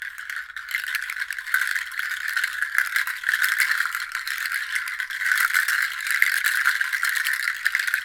Index of /90_sSampleCDs/Roland - Rhythm Section/PRC_Water Perc/PRC_H2O Castanet
PRC WATERC01.wav